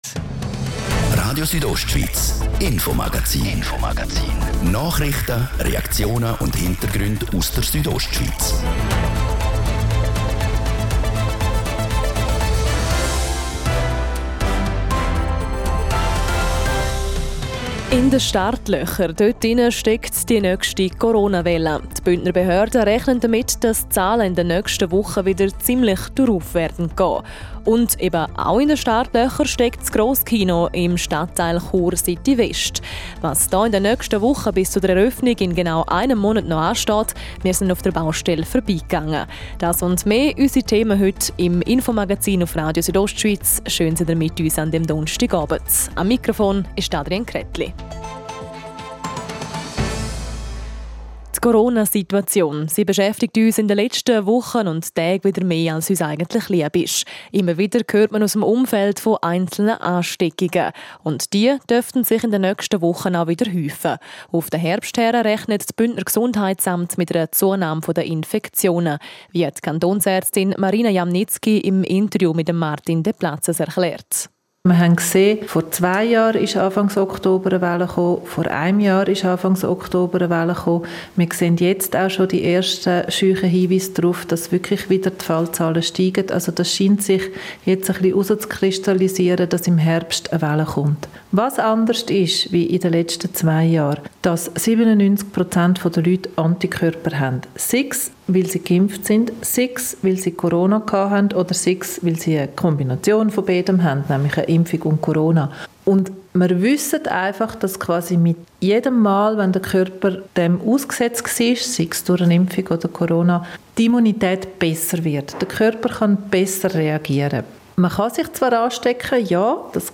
Schlussspurt bei den Bauarbeiten am Grosskino in Chur West - die Reportage.